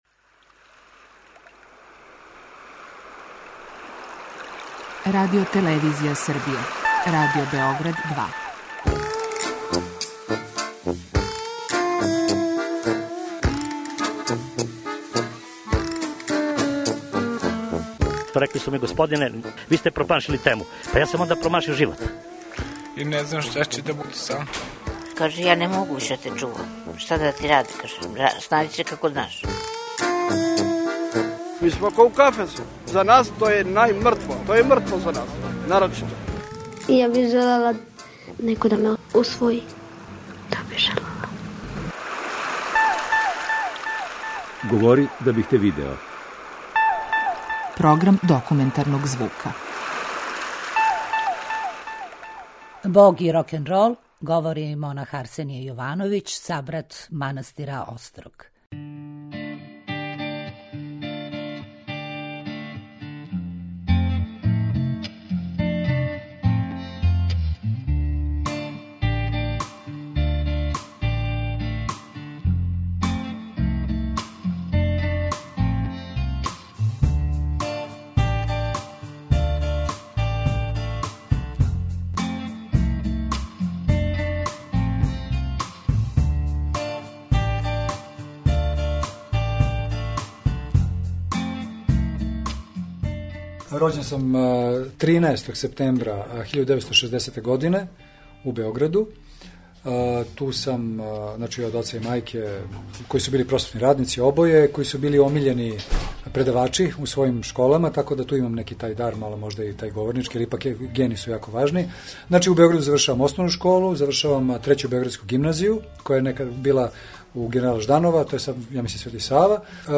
Докумантарни програм